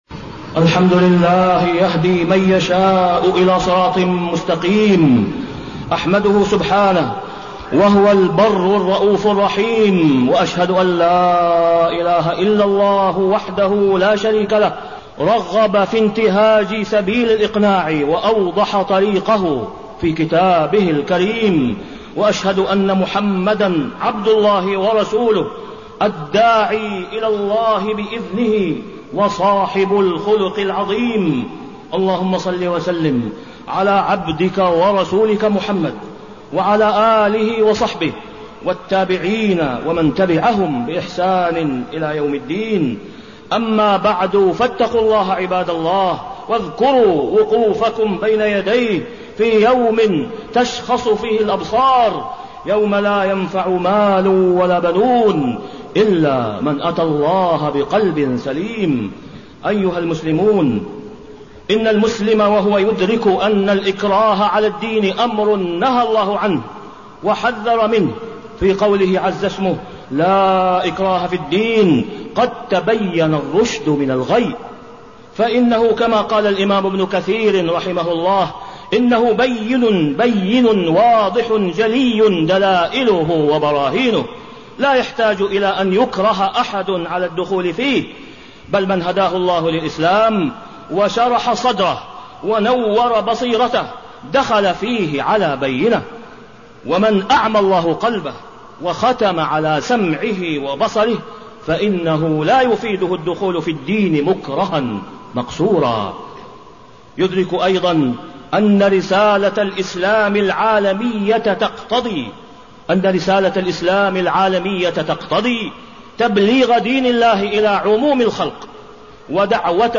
تاريخ النشر ٧ ربيع الثاني ١٤٣٠ هـ المكان: المسجد الحرام الشيخ: فضيلة الشيخ د. أسامة بن عبدالله خياط فضيلة الشيخ د. أسامة بن عبدالله خياط الإقناع وأثره في الإسلام